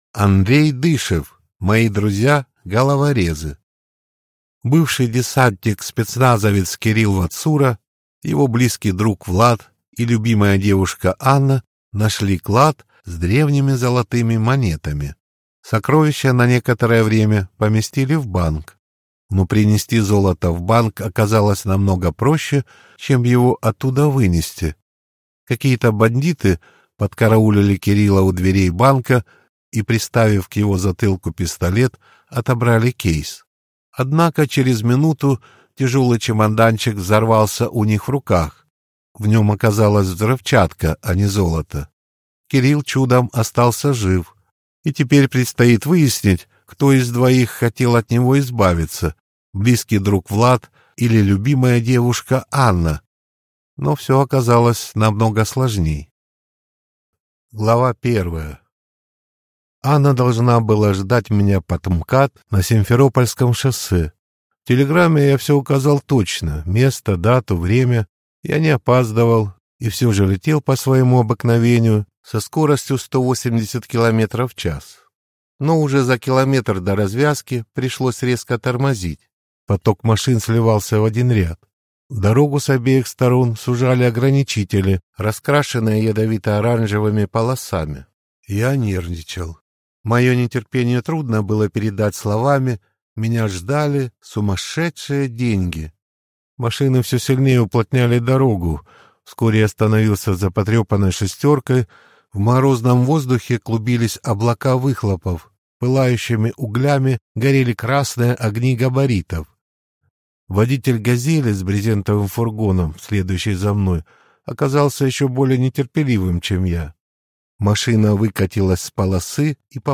Аудиокнига Мои друзья головорезы | Библиотека аудиокниг